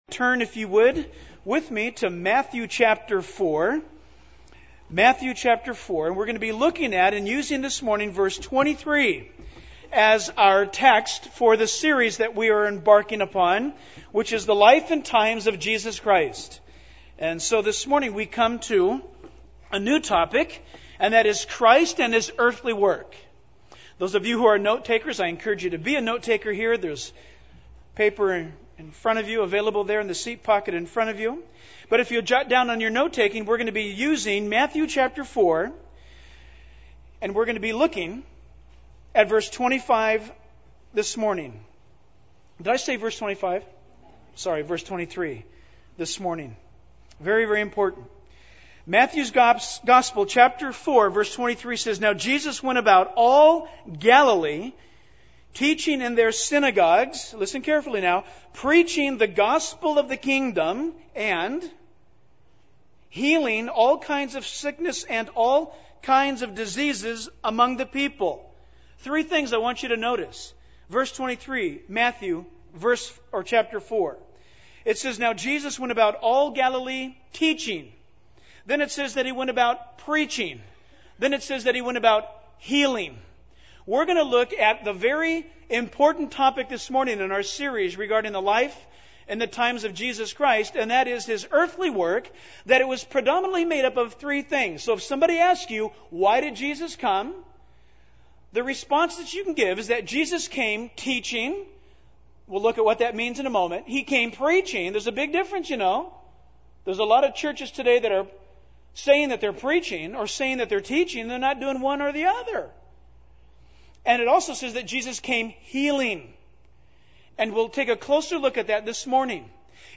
In this sermon, the speaker focuses on the earthly work of Jesus Christ.